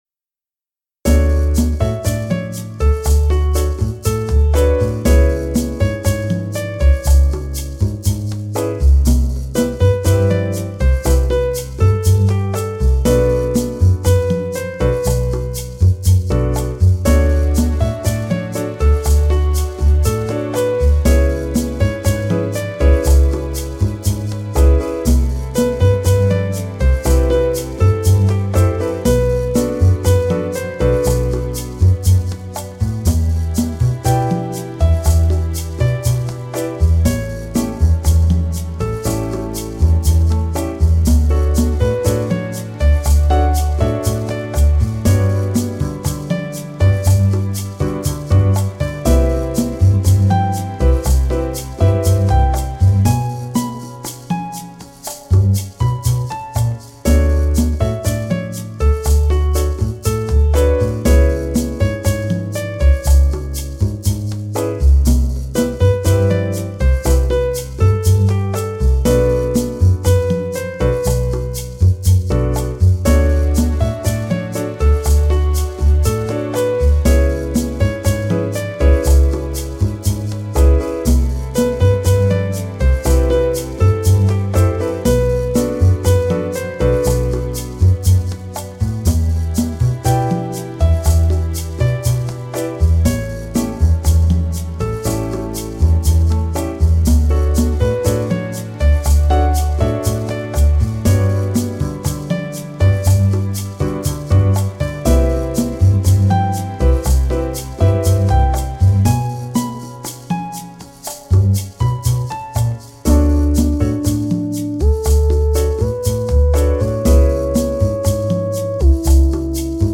bg-bossanova.mp3